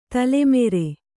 ♪ tale mere